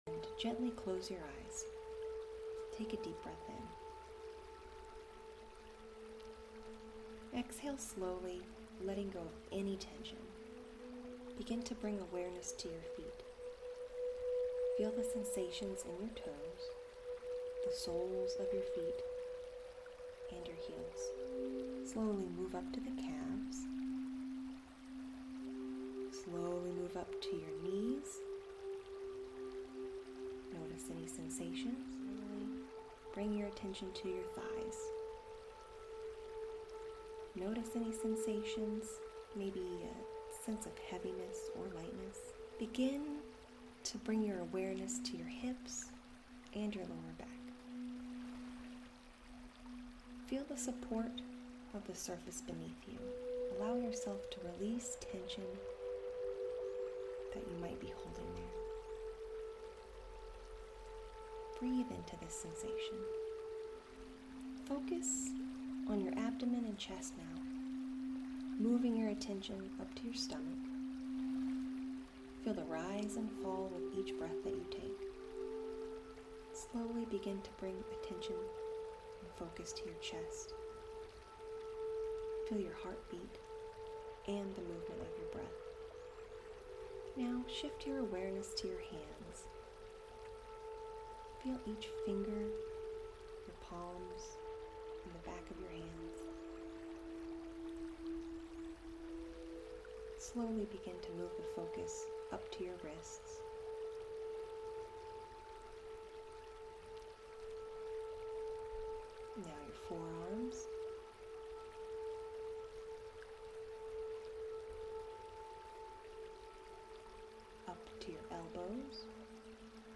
Try this 3-minute body scan with calming water sounds and soothing music. Perfect for stress relief, improved focus, and a quick relaxation boost.